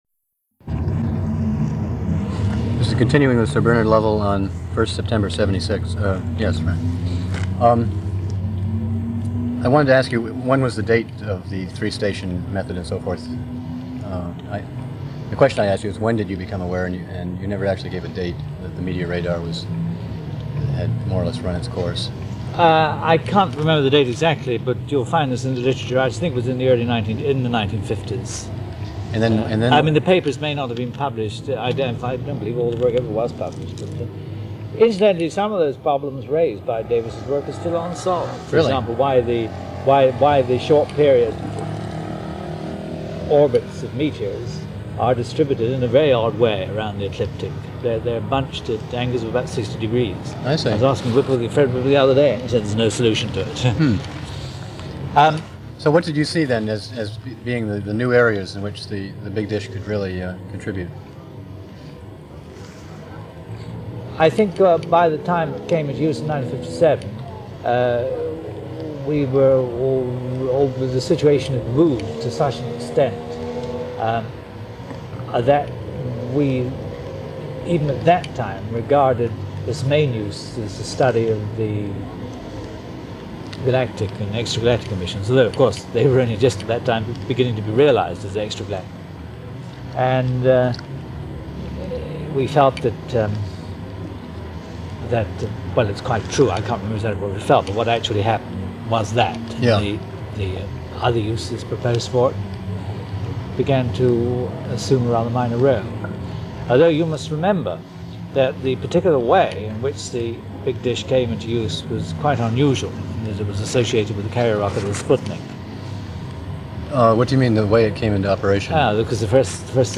Interview with A. C. Bernard Lovell
We are grateful for the 2011 Herbert C. Pollock Award from Dudley Observatory which funded digitization of the original cassette tapes, and for a 2012 grant from American Institute of Physics, Center for the History of Physics, which funded the work of posting these interviews to the Web.